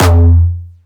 Jumpstyle Kick Solo